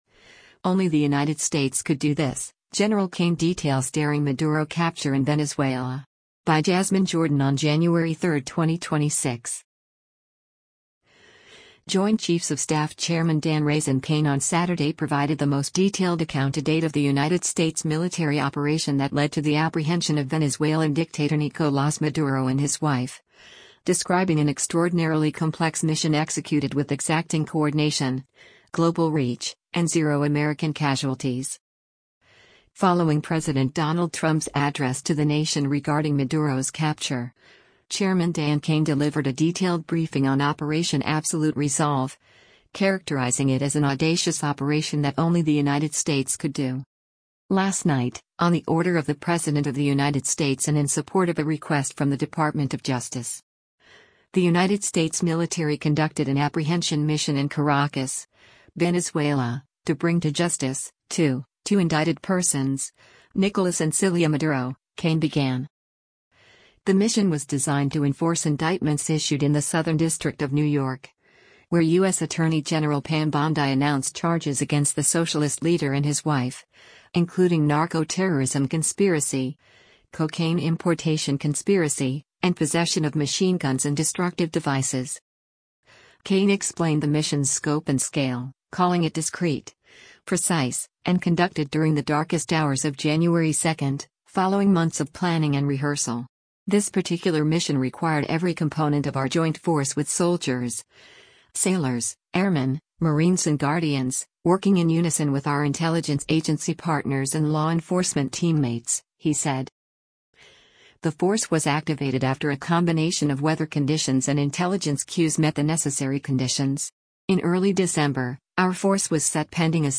Chairman of the Joint Chiefs of Staff Dan Caine speaks during a press conference with U.S.
Following President Donald Trump’s address to the nation regarding Maduro’s capture, Chairman Dan Caine delivered a detailed briefing on Operation Absolute Resolve, characterizing it as “an audacious operation that only the United States could do.”